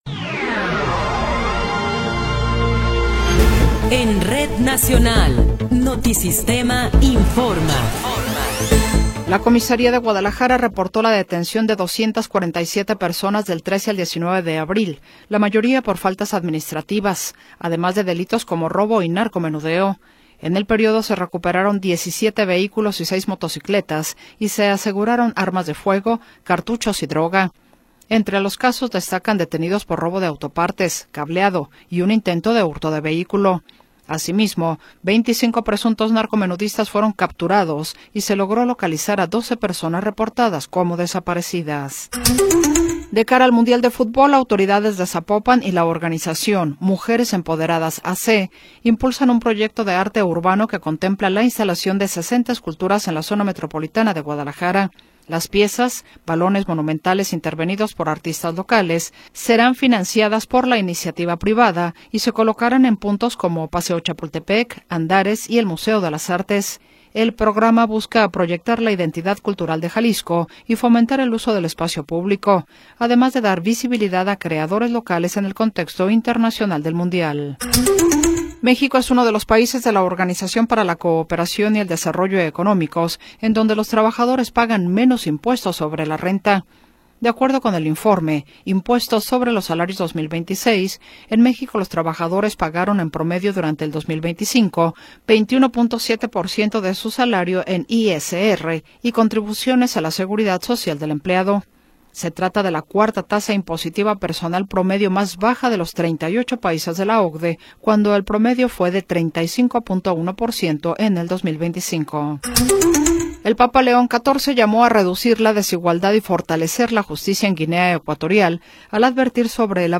Noticiero 15 hrs. – 25 de Abril de 2026
Resumen informativo Notisistema, la mejor y más completa información cada hora en la hora.